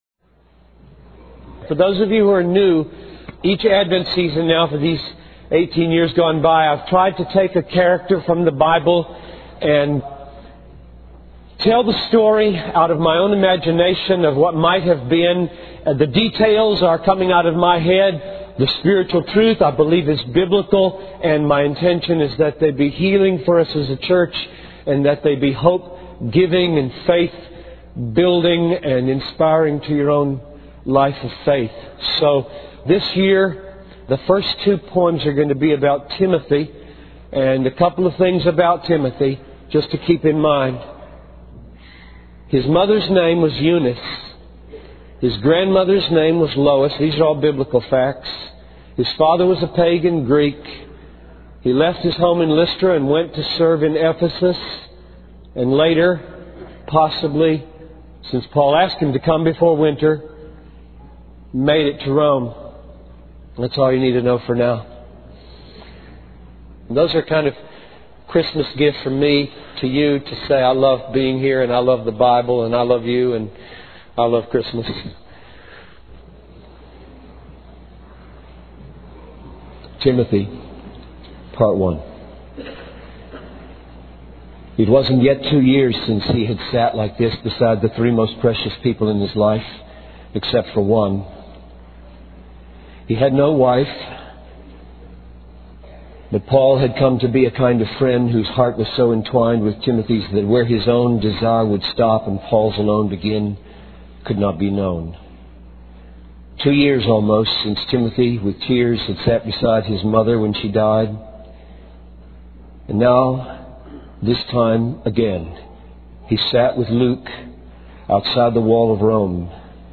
In this sermon transcript, a young man stands outside his mother's door, praying for her healing or preparation for death.